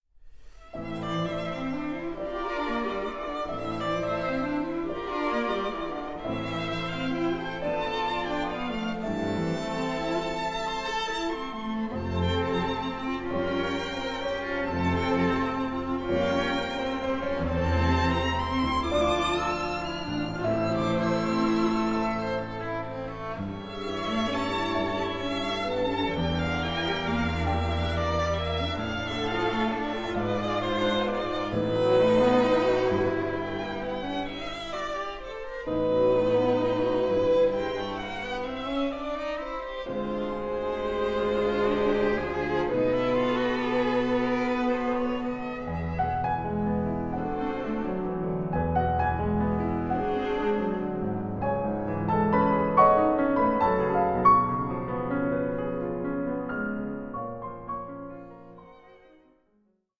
for violin, piano and string quartet